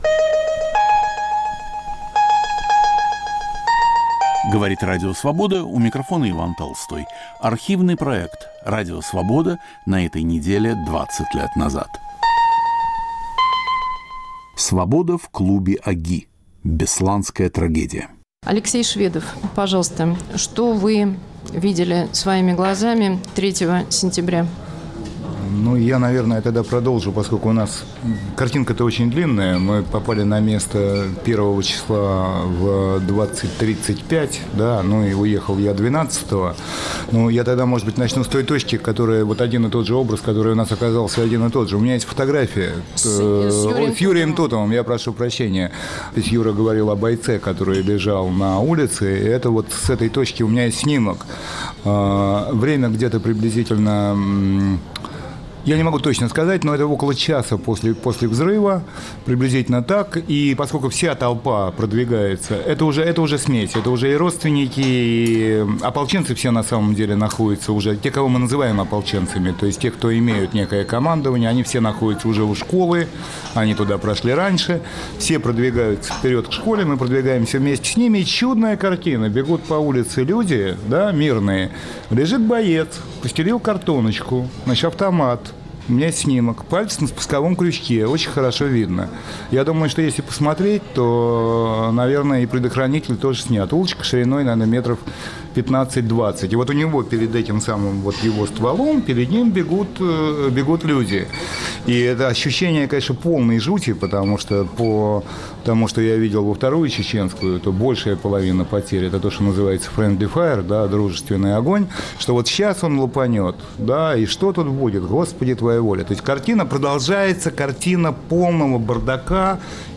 Месяц спустя после штурма бесланской школы №1 рассказывают свидетели события: фотографы и военные корреспонденты.